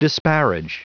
Prononciation du mot disparage en anglais (fichier audio)
Prononciation du mot : disparage